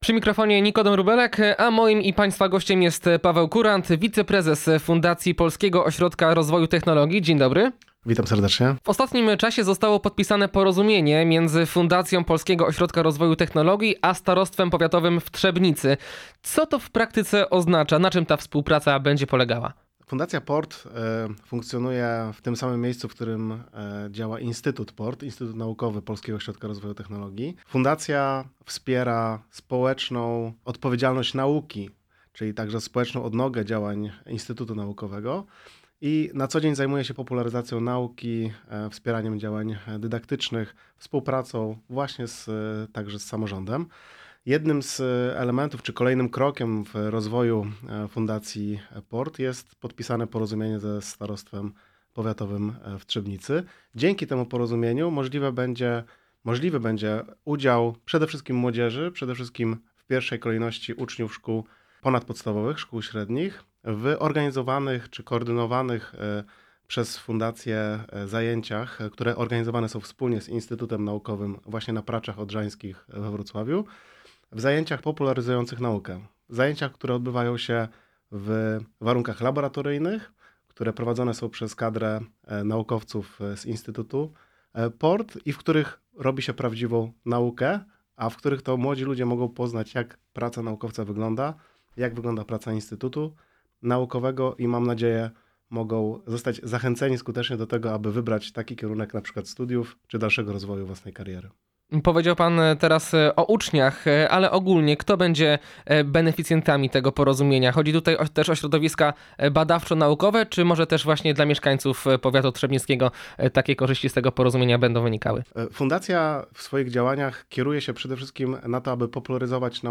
O szczegółach porozumienia i o samej Fundacji PORT rozmawiamy